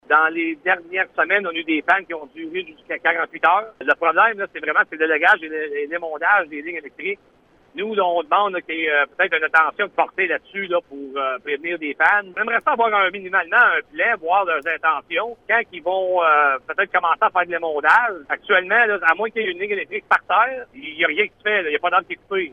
Le maire suppléant de la municipalité, Ghyslain Robert, espère qu’Hydro-Québec adoptera, au minimum, un plan d’intervention :